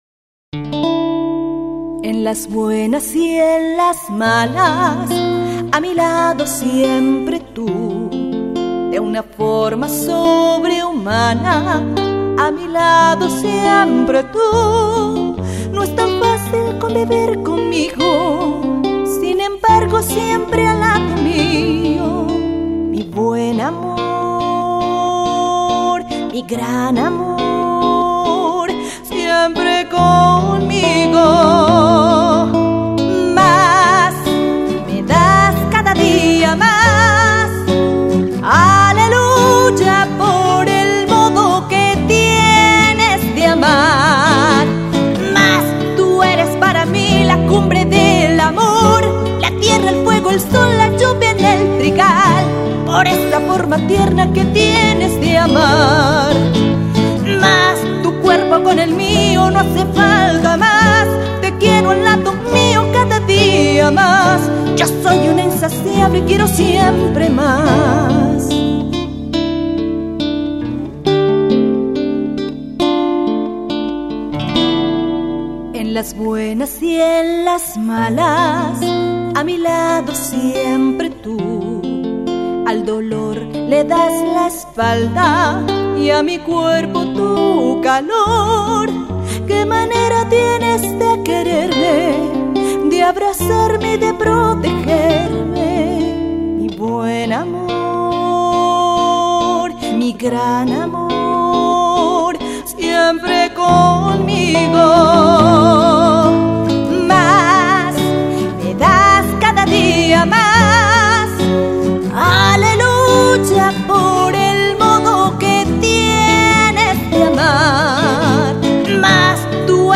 de una manera acústica
guitarra